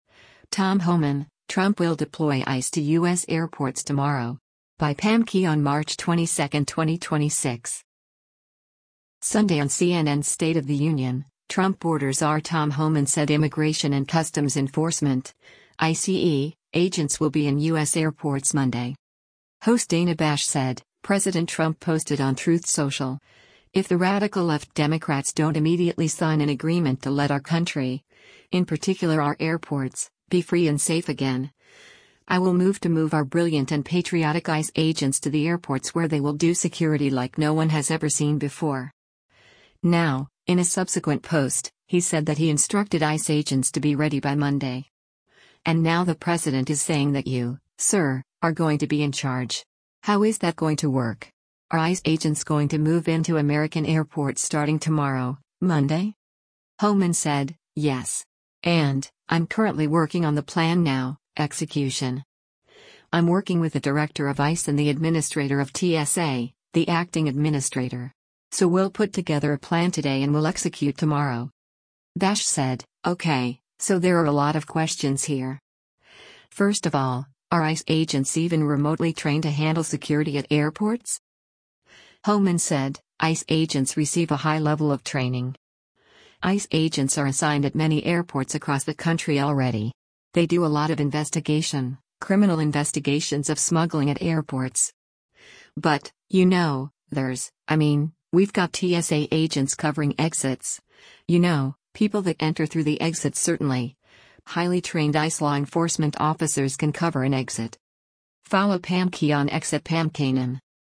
Sunday on CNN’s “State of the Union,” Trump border czar Tom Homan said Immigration and Customs Enforcement (ICE) agents will be in U.S. airports Monday.